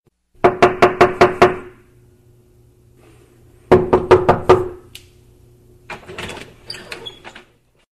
stuk-v-dver_24581.mp3